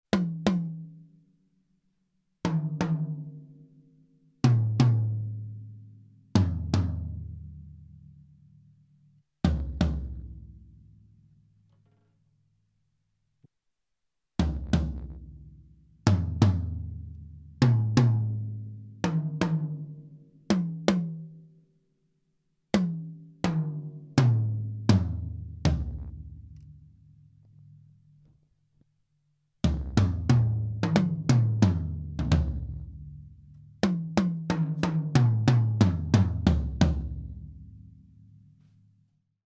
Der Wechsel auf Evans EC2S Clear ist vollzogen. Habe einen kleinen Vergleich aufgenommen, der natürlich nicht ganz fair ist, da die Remo Empeor nicht gerade neu sind.
Nur Tommics Remo